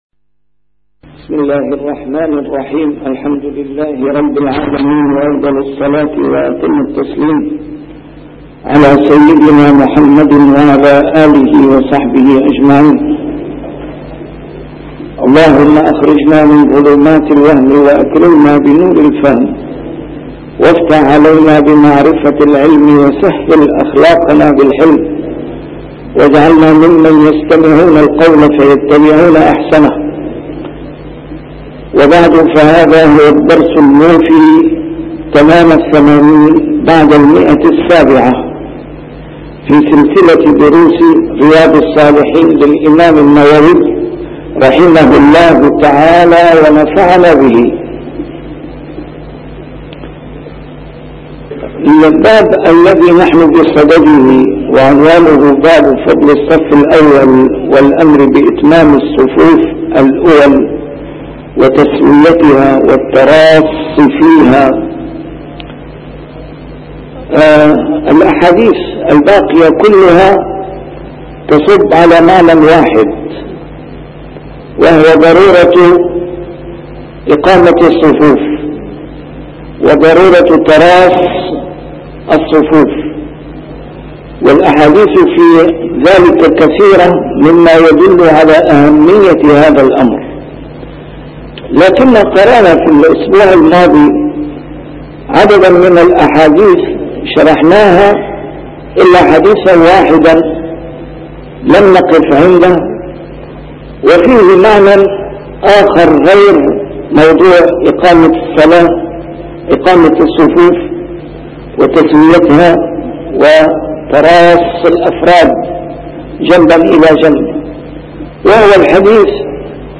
A MARTYR SCHOLAR: IMAM MUHAMMAD SAEED RAMADAN AL-BOUTI - الدروس العلمية - شرح كتاب رياض الصالحين - 780- شرح رياض الصالحين: فضل الصف الأول